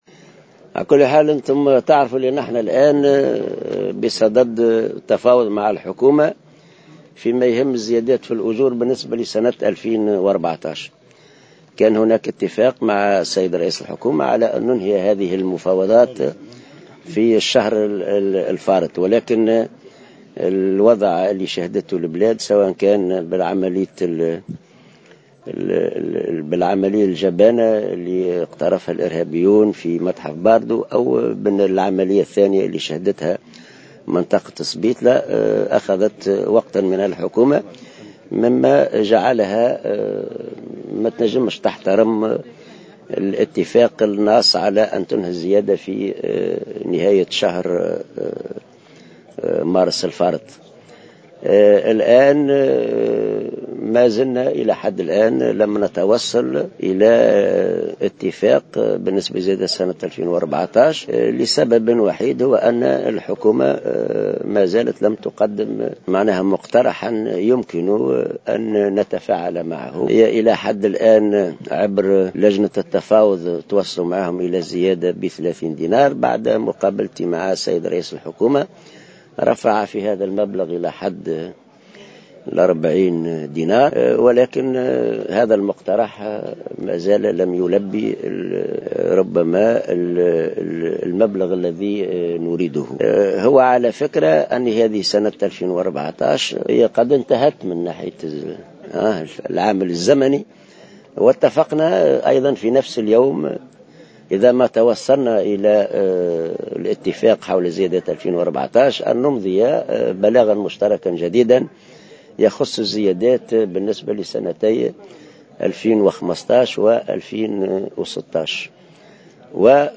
أكد الأمين العام للاتحاد العام التونسي للشغل حسين العباسي في تصريح إعلامى صباح اليوم الإثنين أنه لم يتم التوصل إلى اتفاق مع الحكومة بشأن الزيادة في الأجور لسنة 2014 لسبب وحيد وهو ان الحكومة لم تقدم إلى حد الان مقترحا يمكن التفاعل معه وفق قوله.